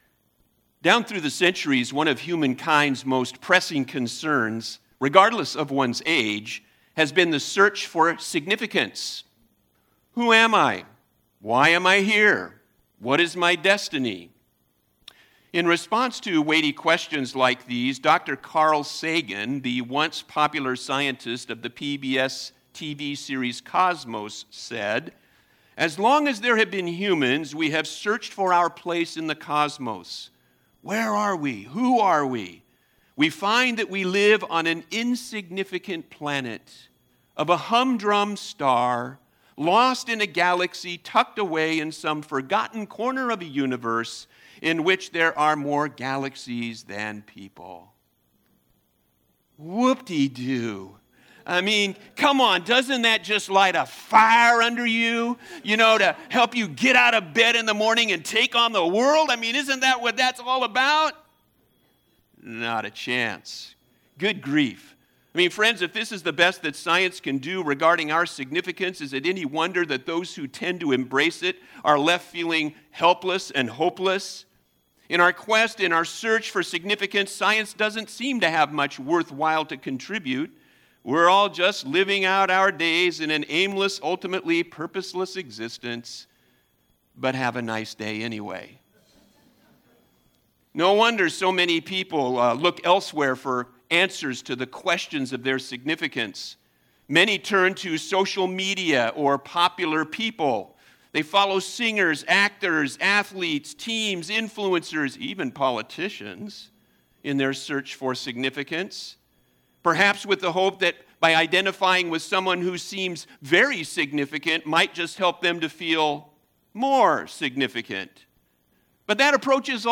Special Sermon Passage